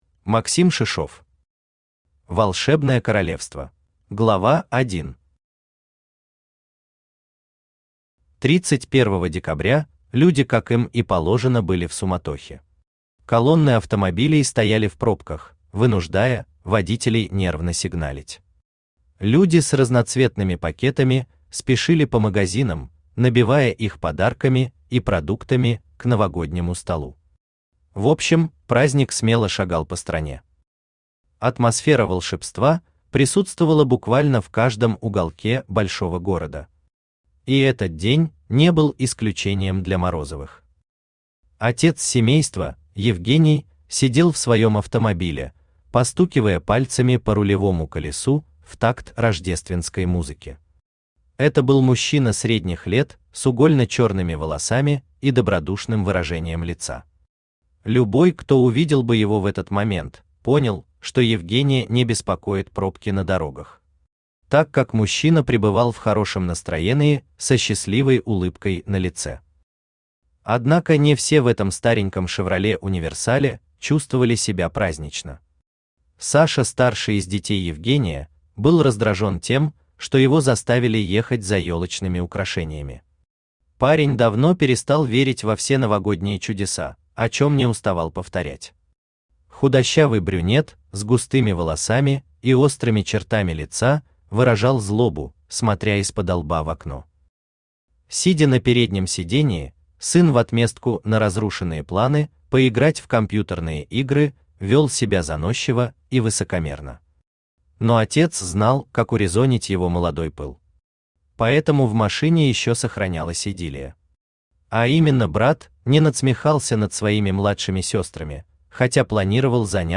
Aудиокнига Волшебное королевство Автор Максим Шишов Читает аудиокнигу Авточтец ЛитРес.